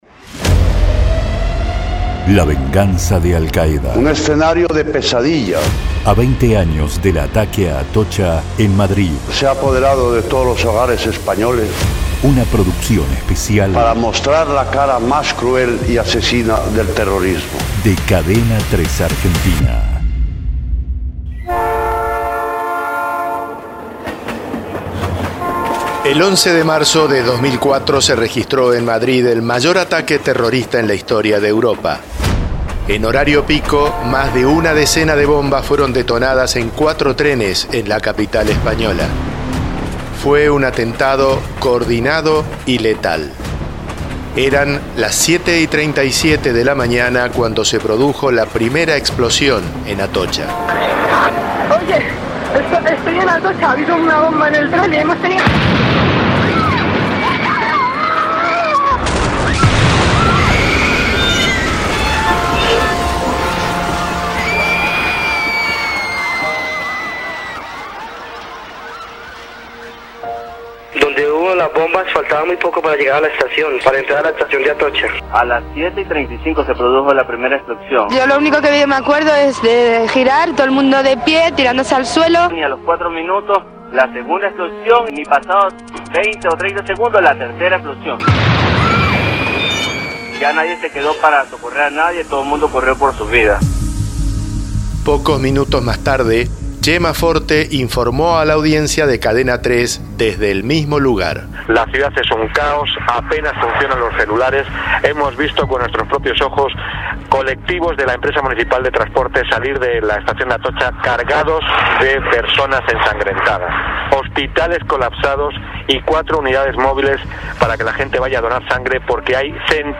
Cadena 3 presenta un informe especial sobre el atentado que dejó 192 muertos y más de 2 mil heridos.
Es una estremecedora descripción del sangriento caos que se vivió en Madrid.